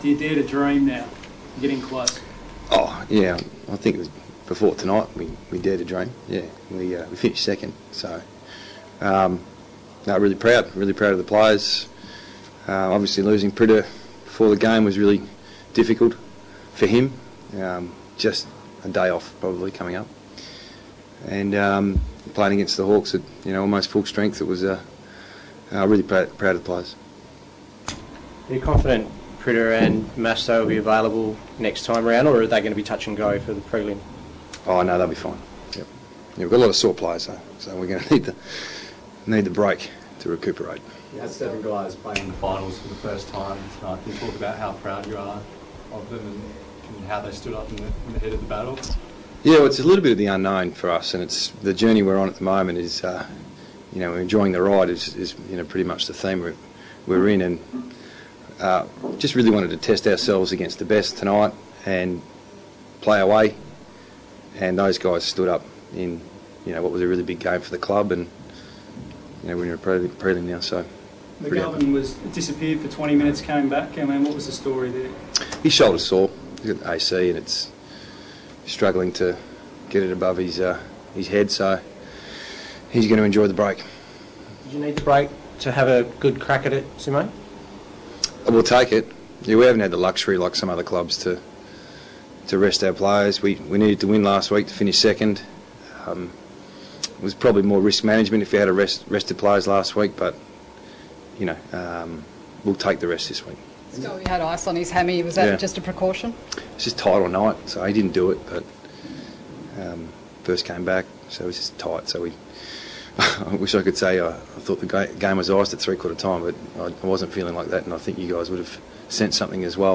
Adam Simpson Post Game Press Conference
press conference with West Coast Eagles coach, Adam Simpson